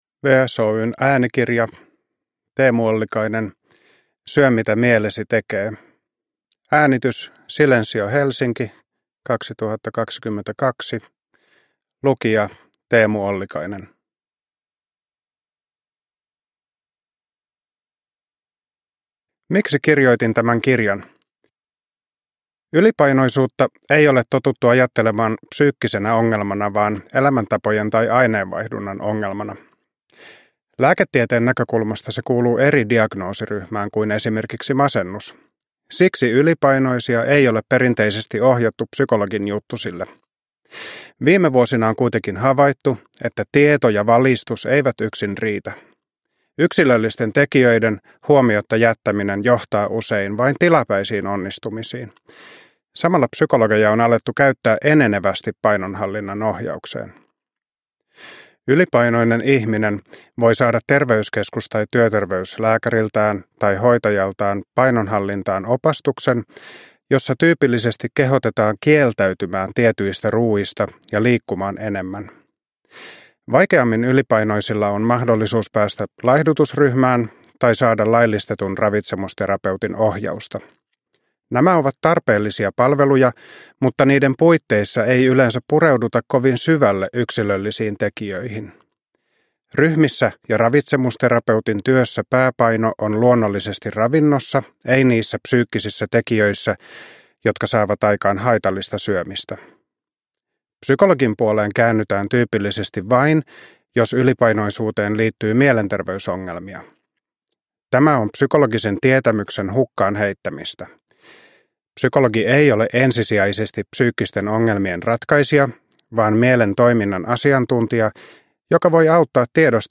Syö mitä mielesi tekee – Ljudbok – Laddas ner